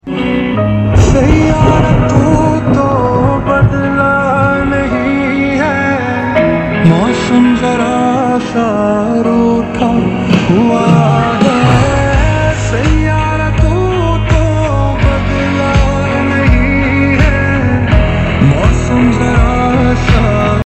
8 Inches Woofer Speaker With Sound Effects Free Download